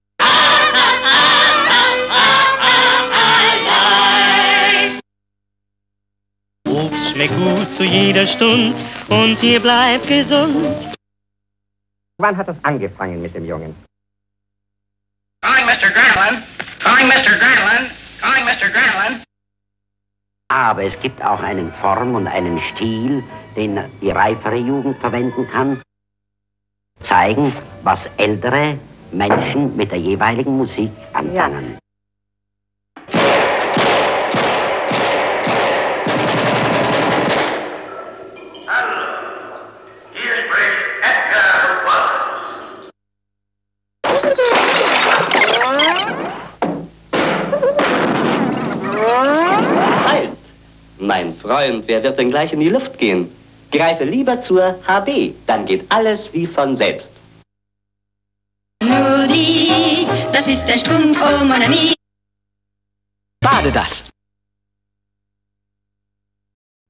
38 Nostalgic Vocals.wav